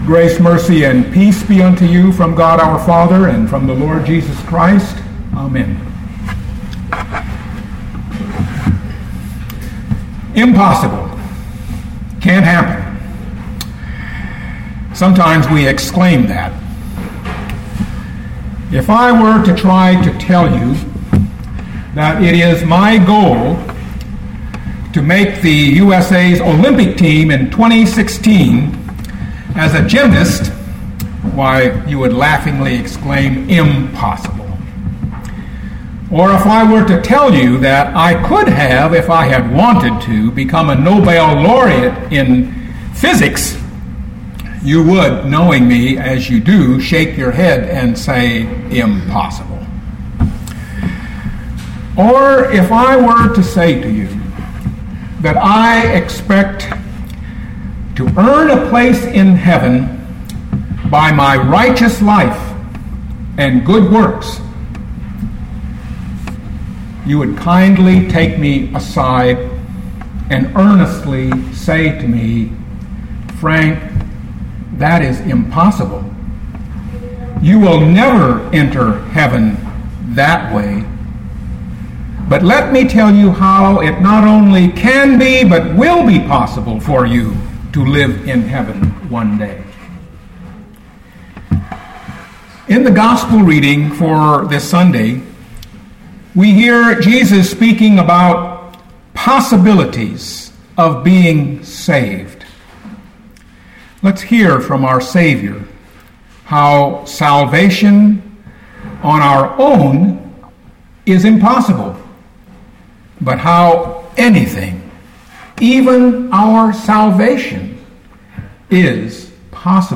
2012 Mark 10:23-31 Listen to the sermon with the player below, or, download the audio.